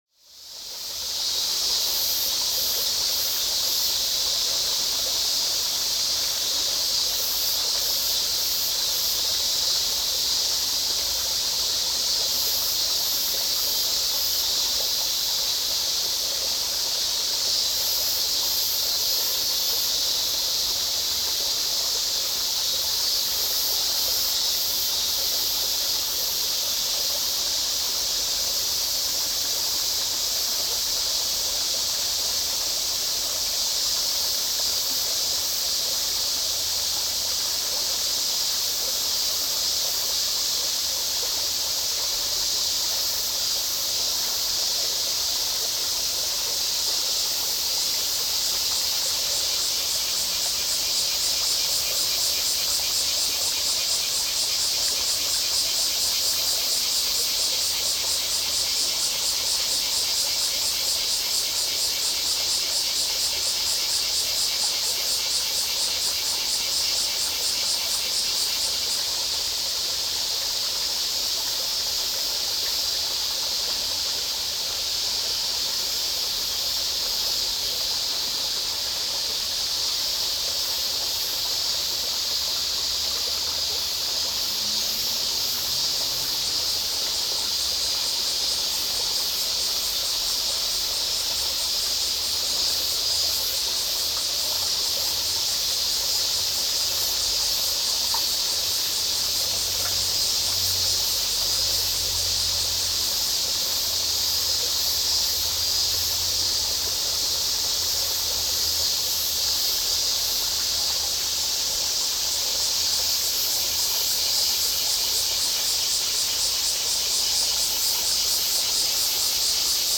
Nezu Shrine, Tokyo - rec 2019